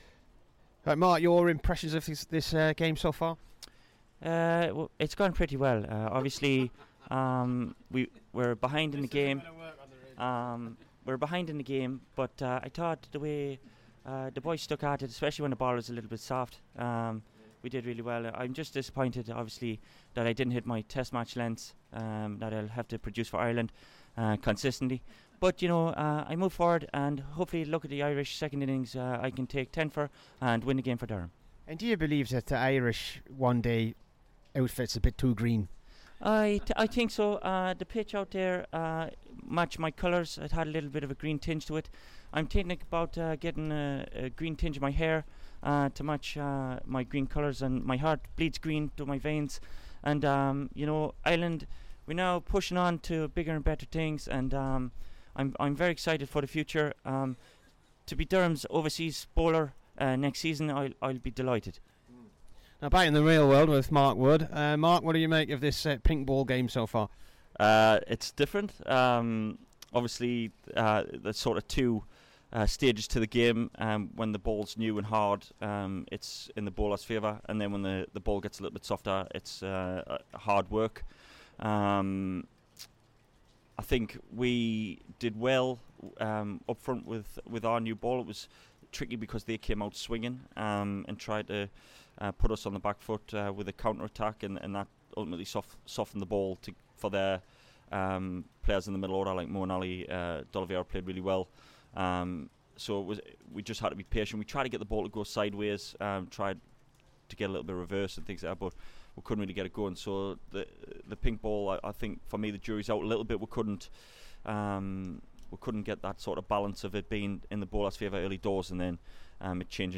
HERE'S THE DURHAM AND ENGLAND FAST BOWLER MARK WOOD AFTER DAY TWO OF THE PINK BALL MATCH V WORCESTERSHIRE.